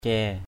/cɛ:/ (d.) trà = thé. tea. manyum aia caiy mv~’ a`% =cY uống nước trà = boire du thé. drink tea.